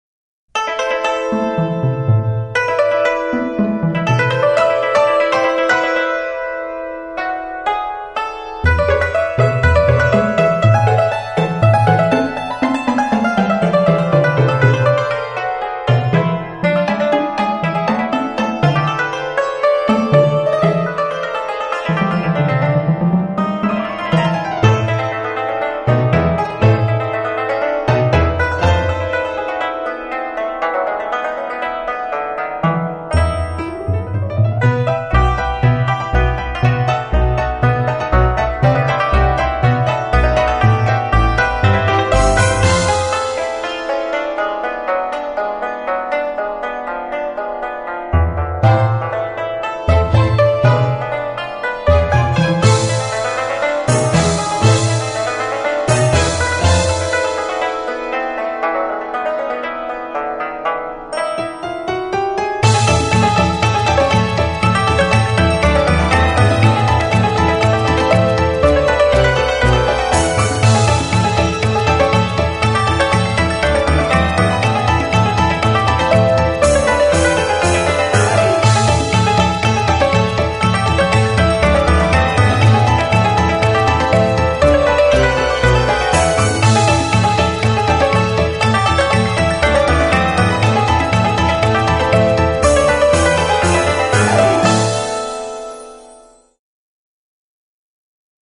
演奏音樂  [休閒SPA音樂]
音樂成份：特別添加──提升心跳的動力節奏、加速血液循環的熱情旋律、充滿青春電力的活力音符